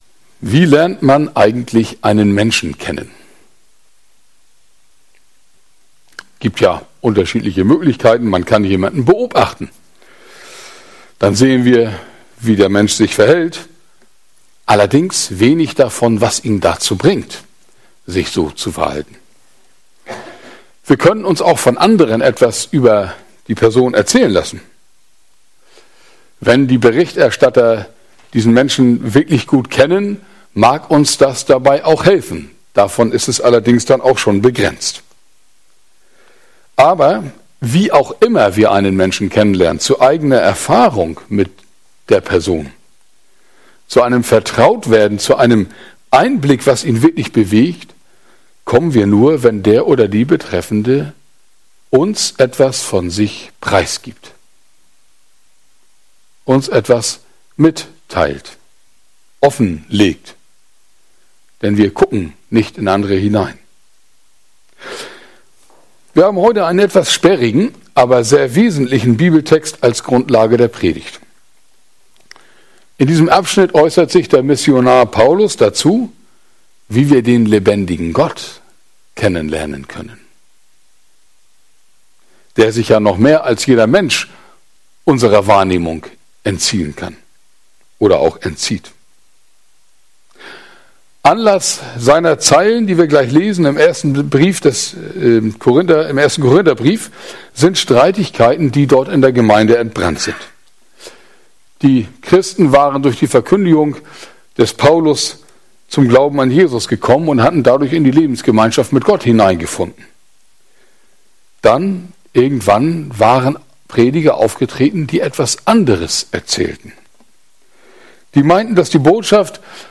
Predigt vom 27. März 2022